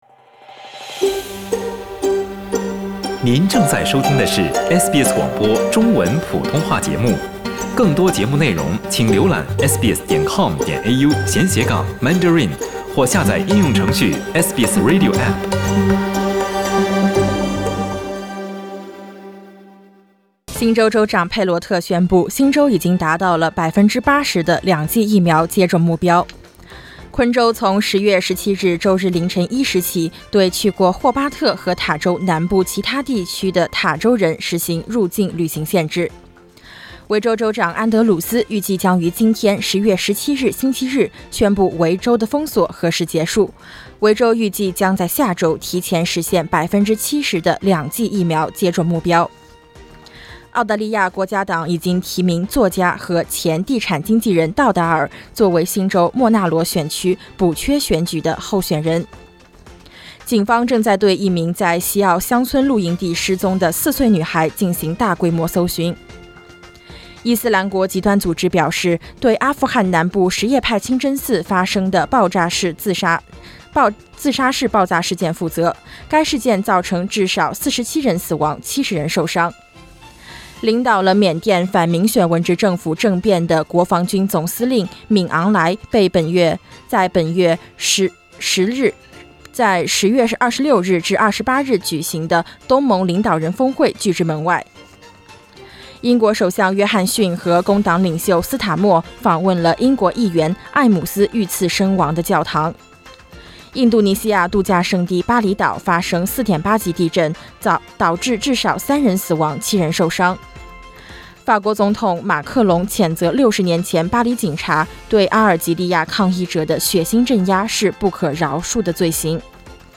SBS早新闻（10月17日）
SBS Mandarin morning news Source: Getty Images